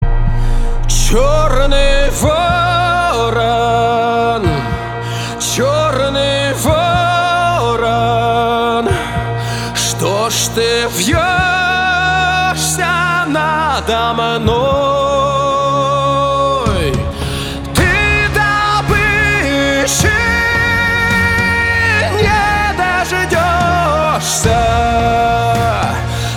поп
красивый мужской голос , чувственные